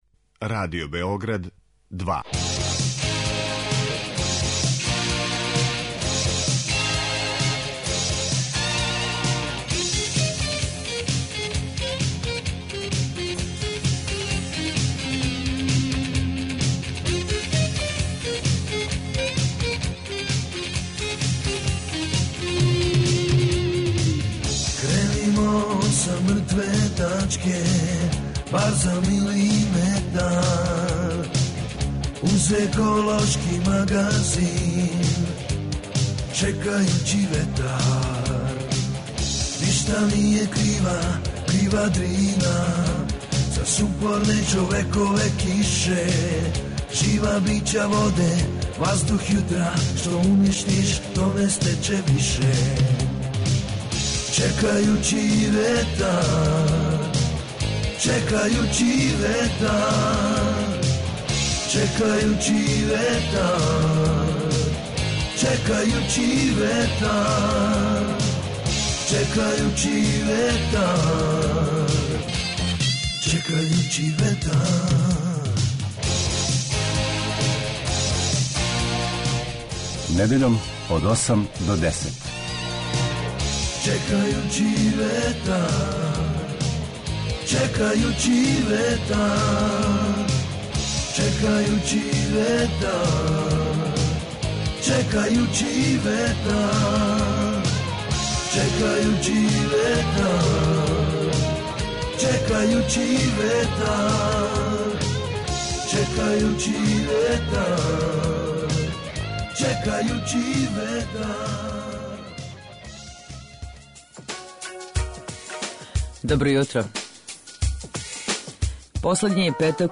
Еколошки магазин
Чућете шта је речено на панел дискусији на ову тему, у оквиру Green фeстa .